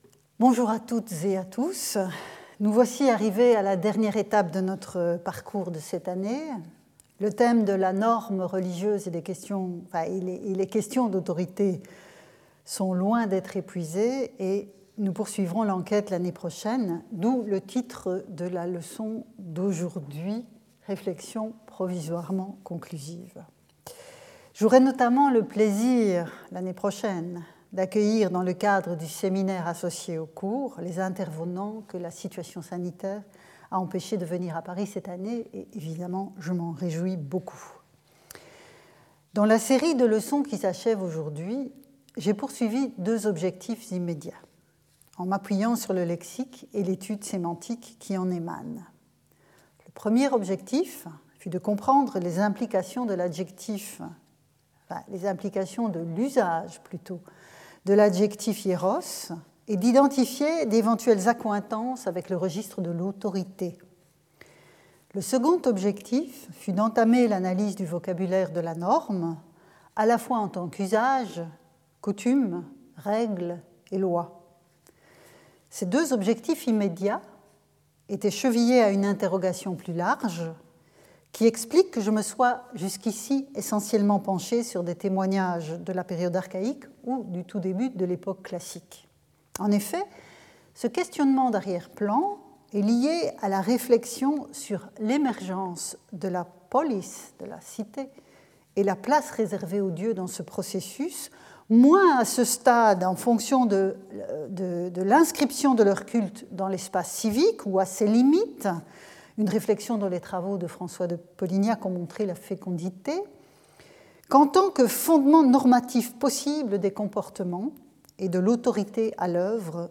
Skip youtube video player Listen to audio Download audio Audio recording Abstract The last lesson of the year offers some interim conclusions, since the theme of religious norms and questions of authority is far from exhausted. After summarizing what we've learned over the weeks about the semantic fields of hieros , themis (with thesmos ) and nomos , we turn to Aeschylus' tragedy of the norm par excellence, The Eumenides.